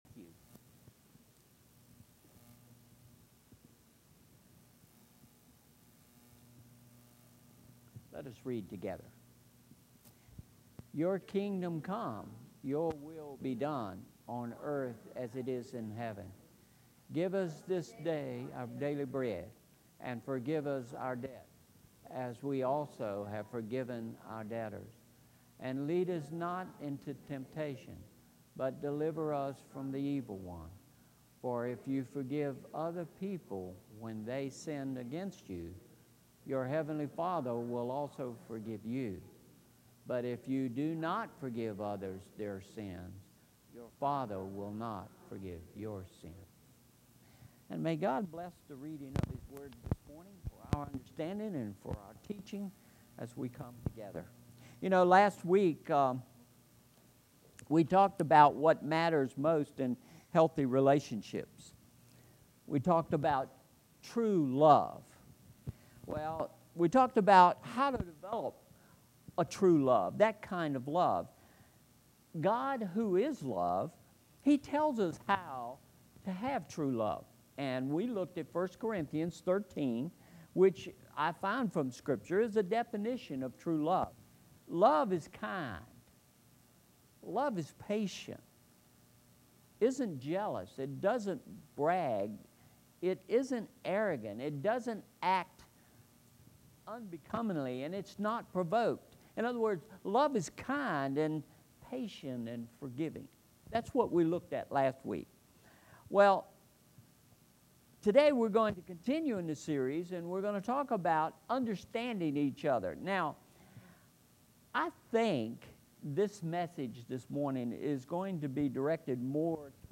Sermon Series: Love Gifts: What Matters Most – Part 2